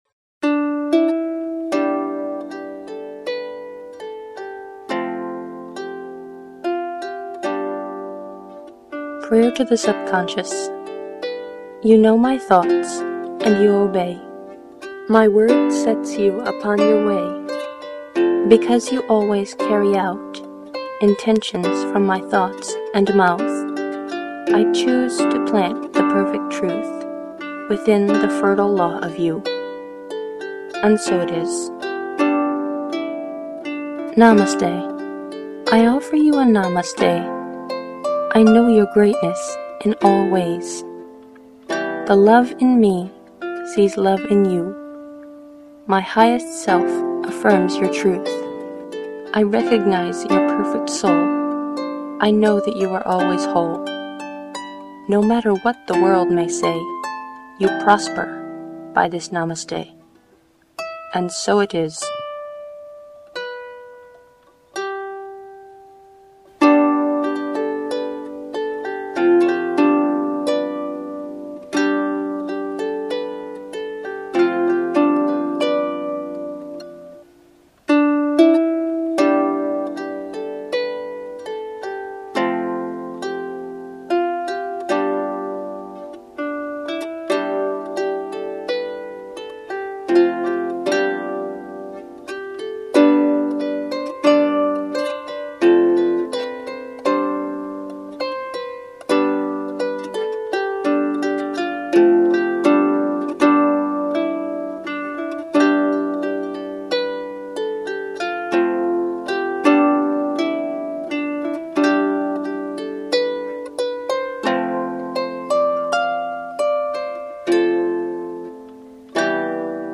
Two poems and a harp meditation to affirm the truth and see love in everything.